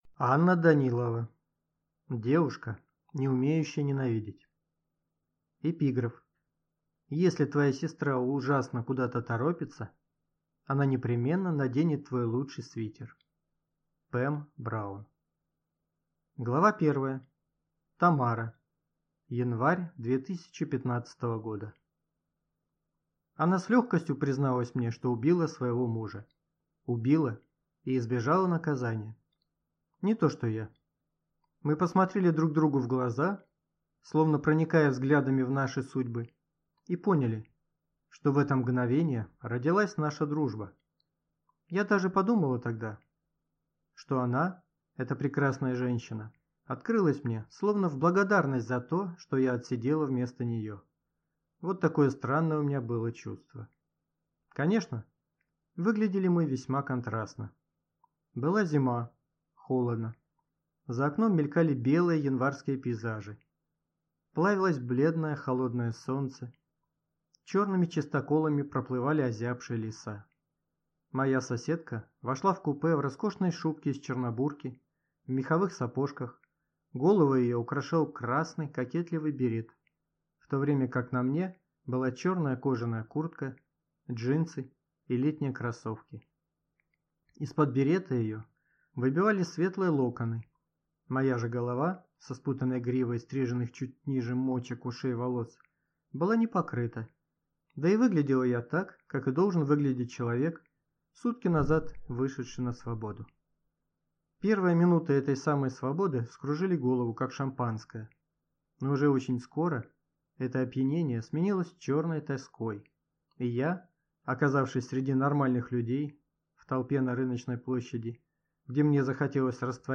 Аудиокнига Девушка, не умеющая ненавидеть | Библиотека аудиокниг